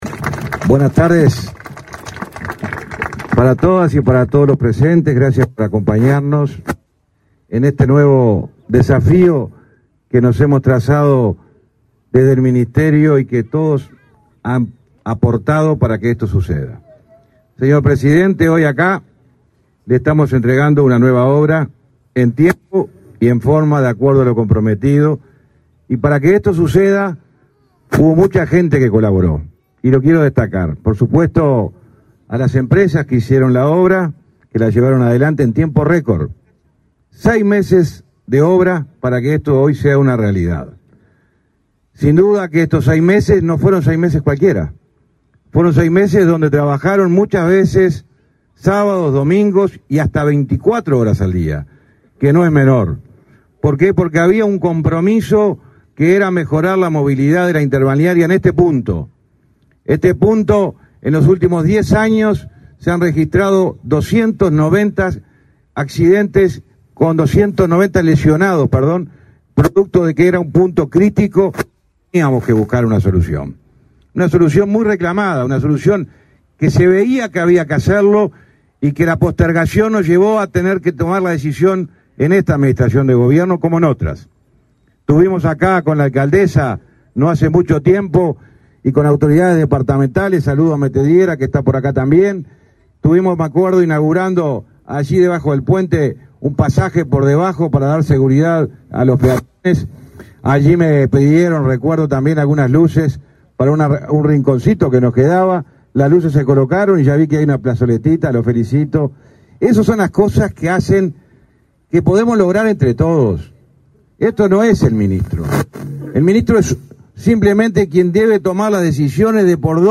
Palabras del ministro de Transporte y Obras Públicas, José Luis Falero
El ministro de Transporte y Obras Públicas, José Luis Falero, disertó en el evento.